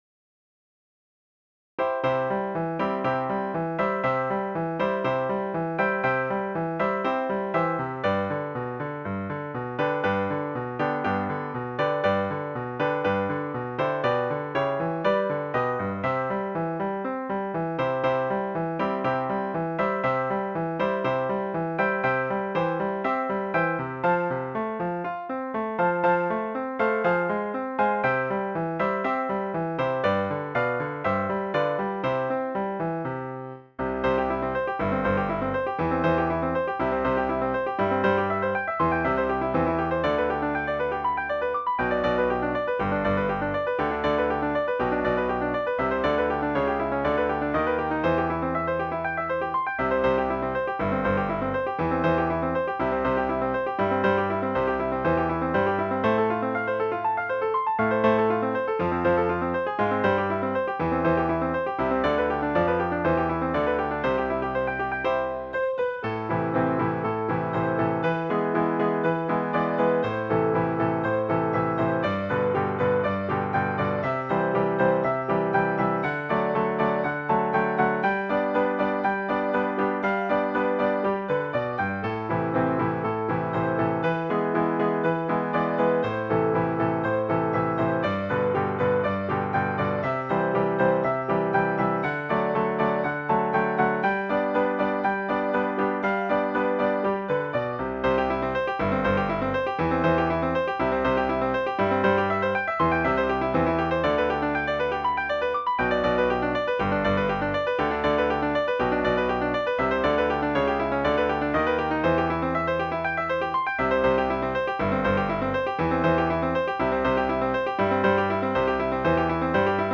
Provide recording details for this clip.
I created this with the Finale composition software.